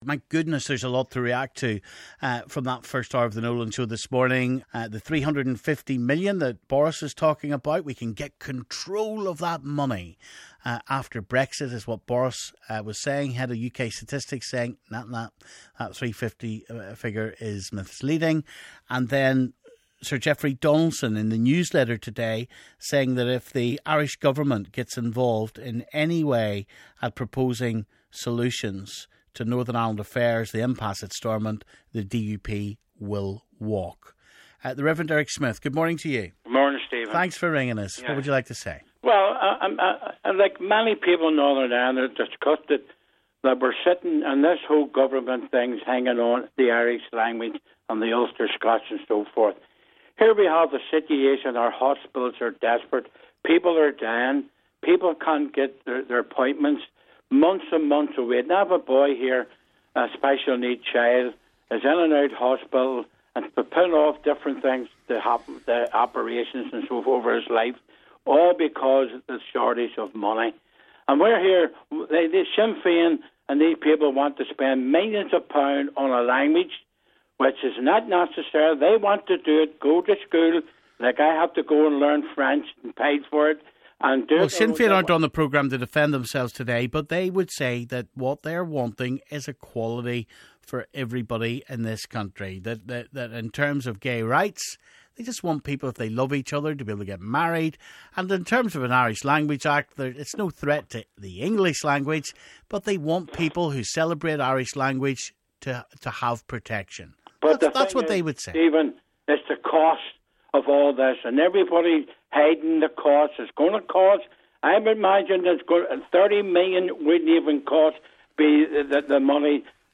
Nolan Callers react to the topics of the day
Reaction from Nolan callers